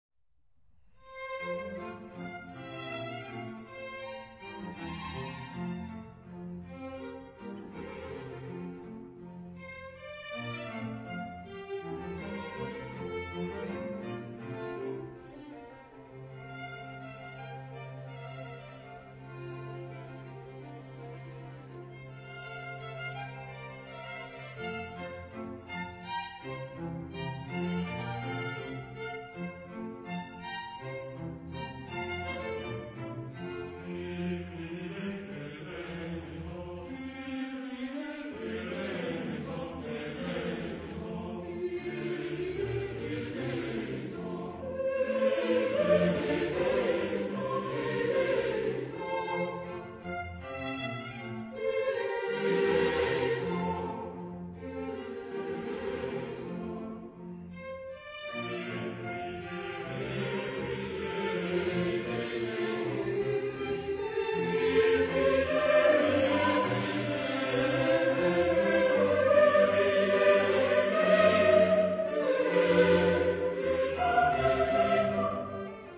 Genre-Style-Forme : Classique ; Sacré
Type de choeur : SATB  (4 voix mixtes )
Solistes : Sopran (1) / Alt (1) / Tenor (1) / Bass (1)
Tonalité : fa majeur